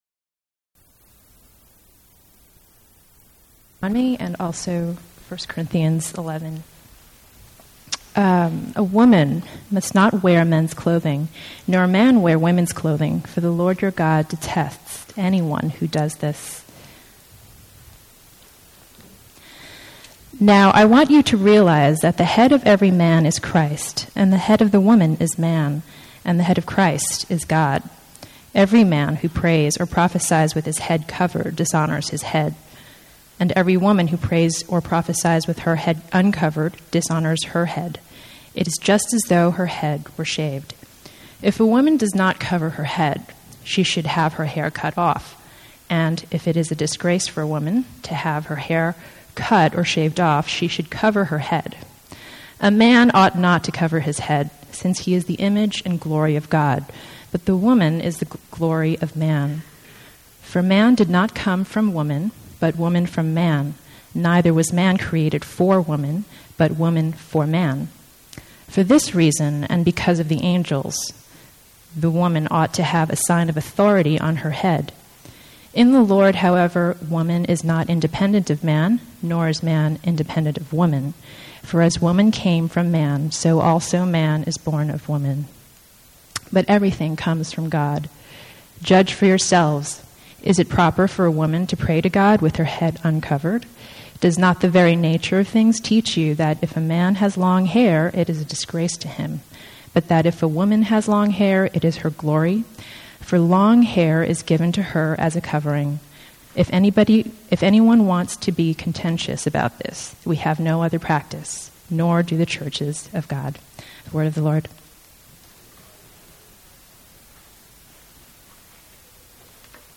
I have to note that there is an error I make in the sermon.